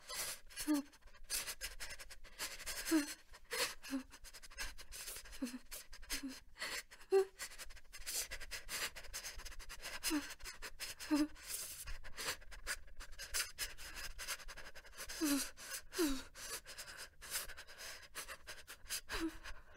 Âm thanh Run Rẩy của Phụ nữ, Con gái
Thể loại: Tiếng con người
Âm thanh này bao gồm tiếng nấc, tiếng rên nhẹ hoặc thậm chí là tiếng thì thầm đầy cảm xúc, phản ánh sự lo lắng, sợ hãi, lạnh lẽo, băng giá...
am-thanh-run-ray-cua-phu-nu-con-gai-www_tiengdong_com.mp3